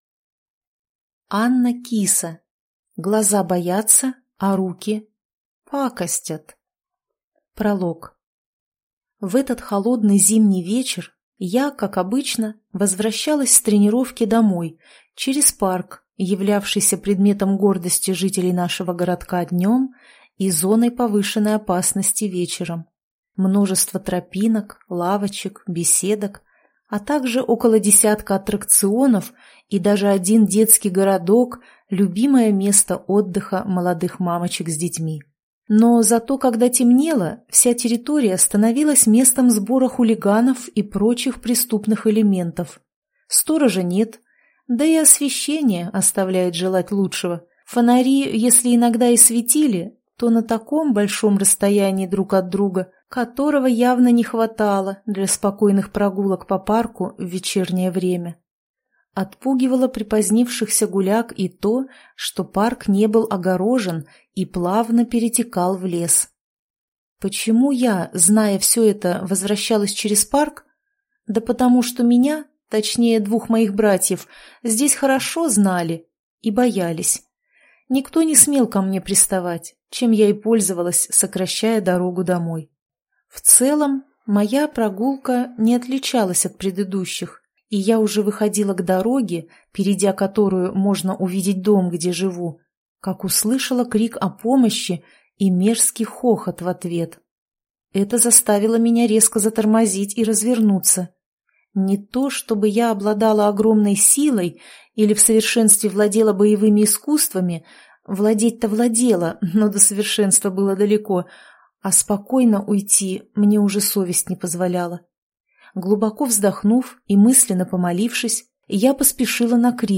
Аудиокнига Глаза боятся, а руки… пакостят | Библиотека аудиокниг